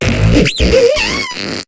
Cri de Moufflair dans Pokémon HOME.